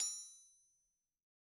Anvil_Hit1_v2_Sum.wav